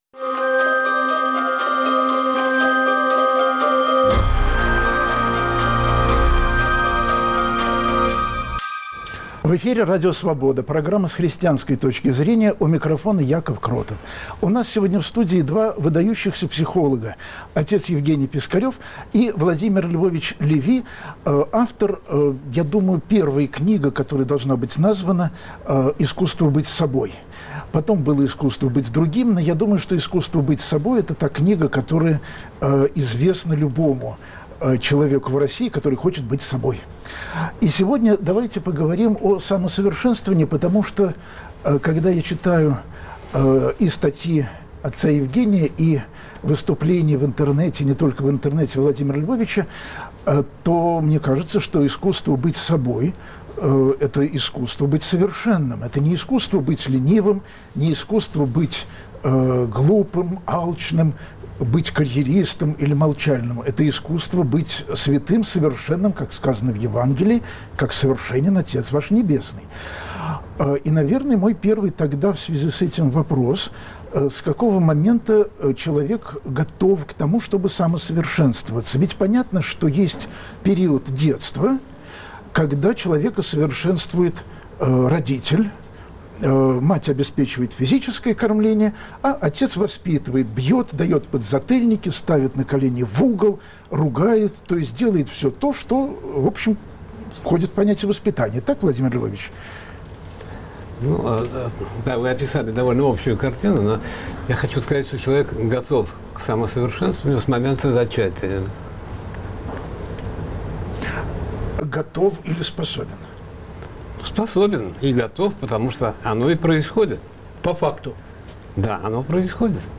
Нужен ли Бог для развития человека? У нас в студии два выдающихся психолога